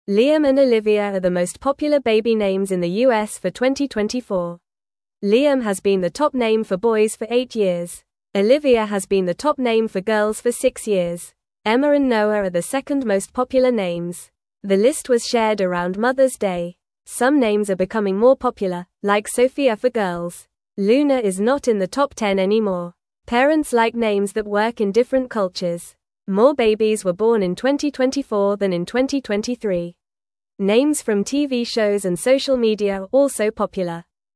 Fast
English-Newsroom-Beginner-FAST-Reading-Liam-and-Olivia-Are-Top-Baby-Names-for-2024.mp3